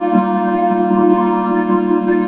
VOCODE LP -R.wav